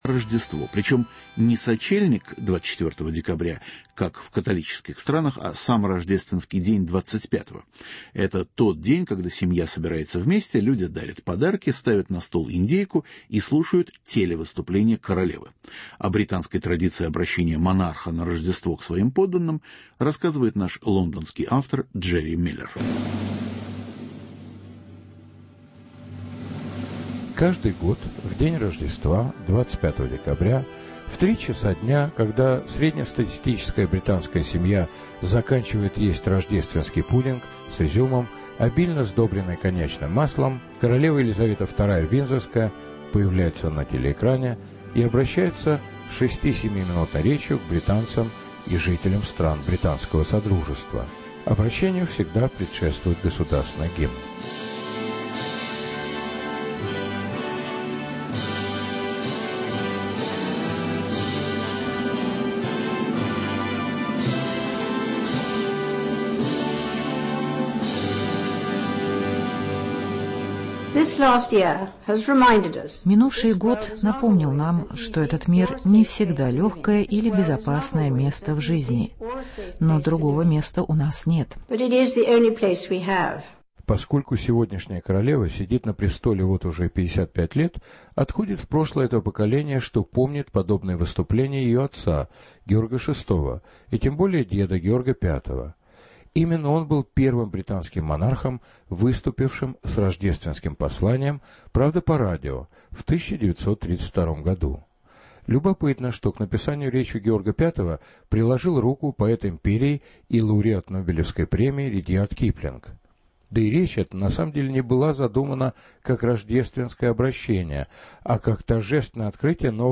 Рождественское обращение королевы Великобритании